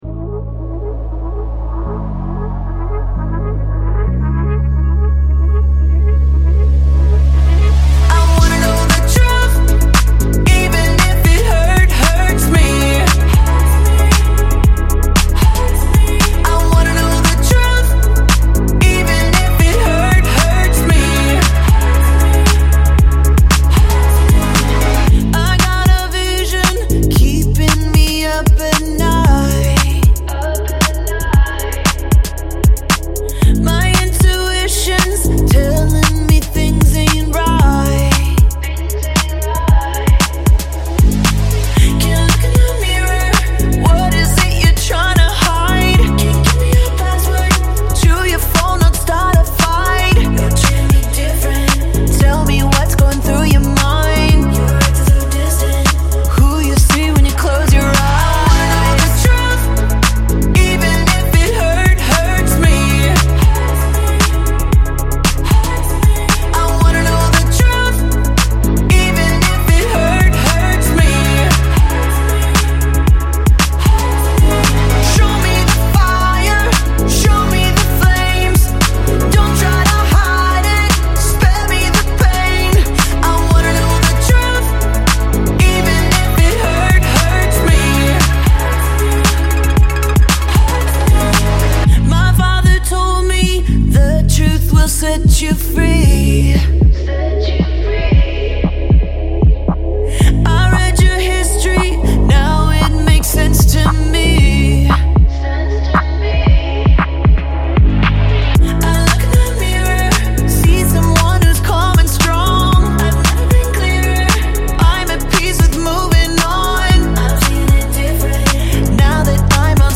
Genre: Christian